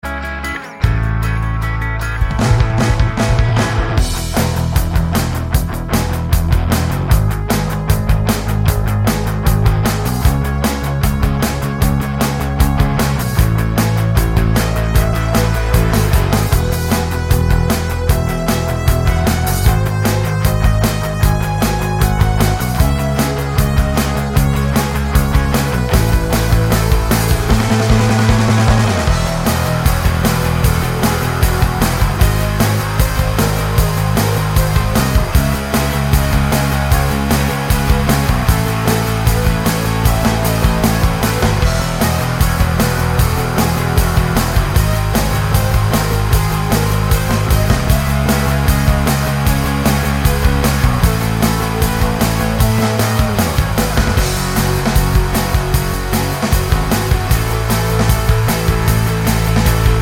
Live Version with No Backing Vocals Rock 3:23 Buy £1.50